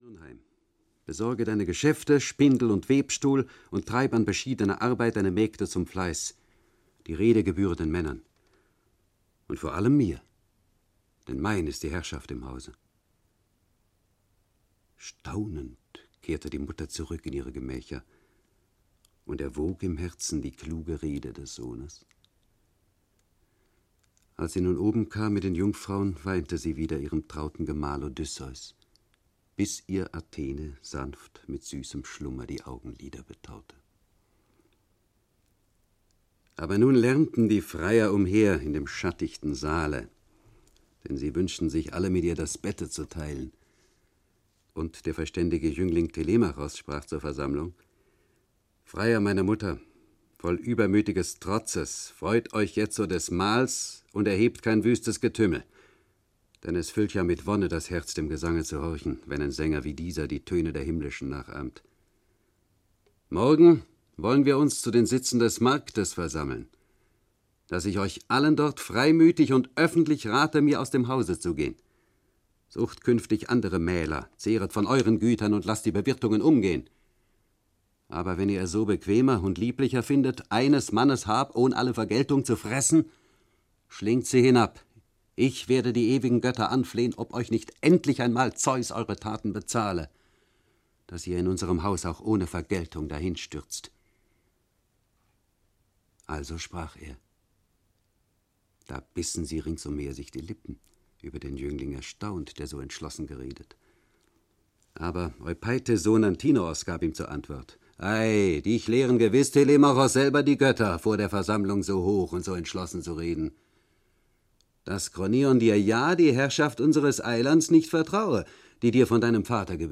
Odyssee - Homer - Hörbuch